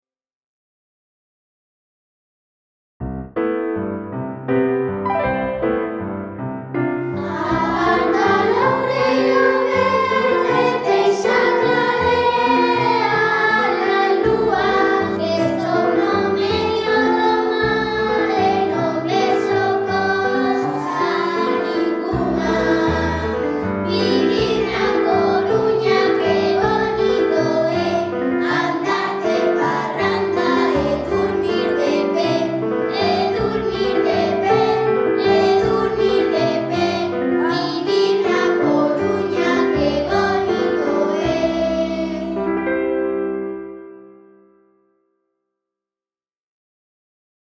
• Harmonía en do maior.
loureiroverde_harmonia_domaior.mp3